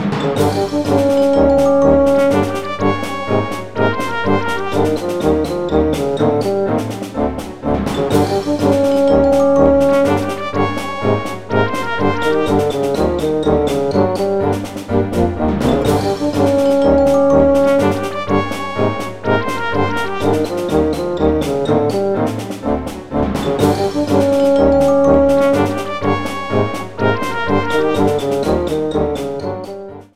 boss battle music
This is a slightly faster variant